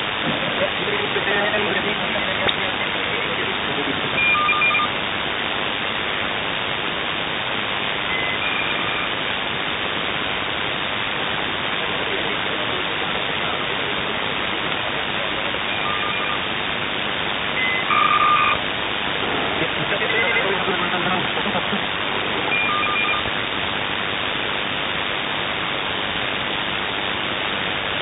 Кодер на КВ
Начало » Записи » Радиоcигналы на опознание и анализ
Закрытие канала в USB на КВ.